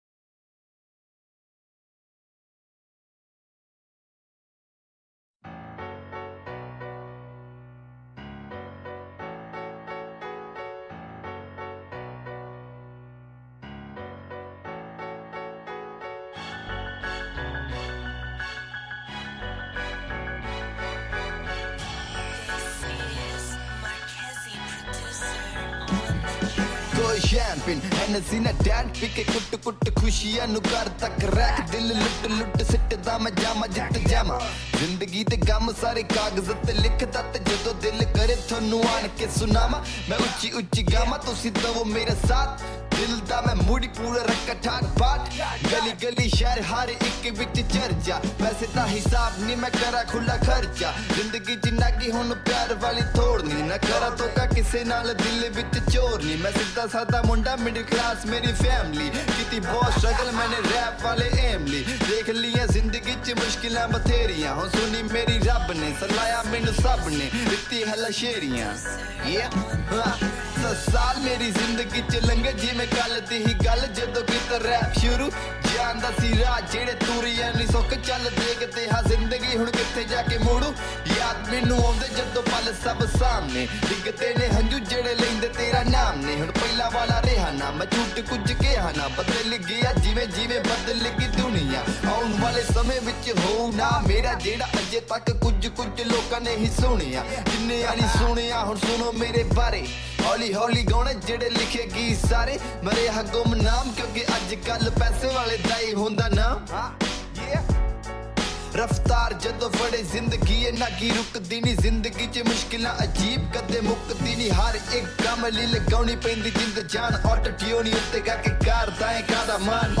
Genre Latest Punjabi Songs